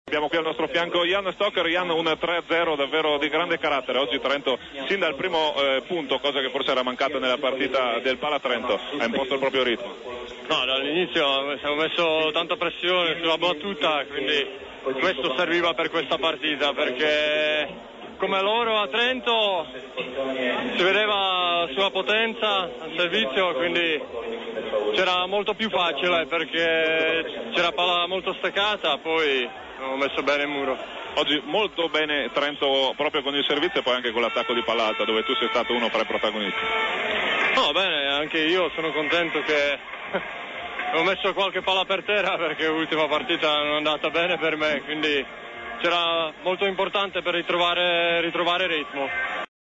Interviste mp3
Jan Stokr nel post Kozle-Trento